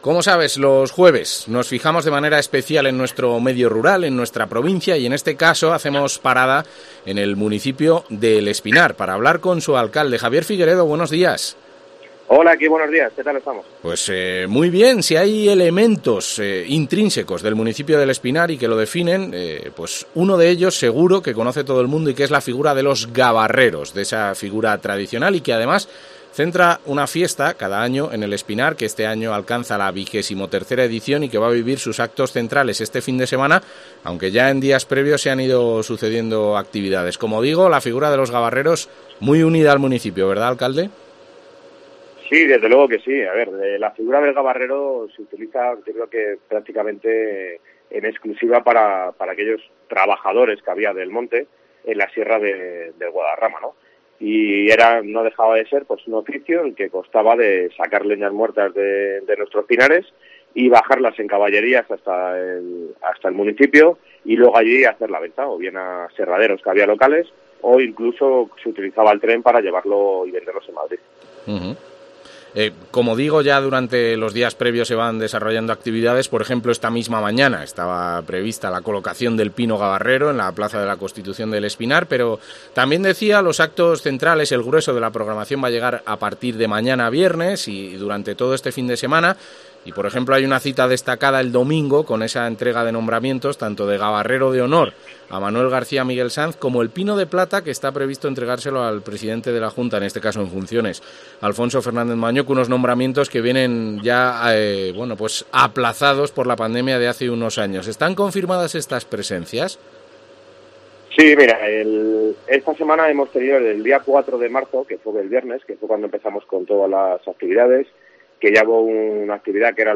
Javier Figueredo, alcalde de El Espinar, habla sobre la XXIII Fiesta de los Gabarreros